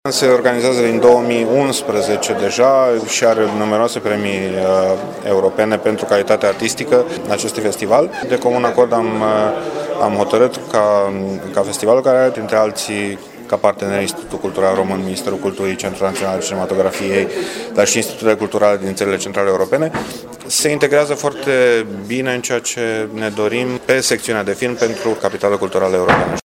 Odată cu aprobarea agendei culturale pe acest an, Consiliul Local Timişoara a alocat şi suma de 180 de mii de lei pentru Festivalul de film, despre care viceprimarul Dan Diaconu spune că va deveni o tradiţie: […]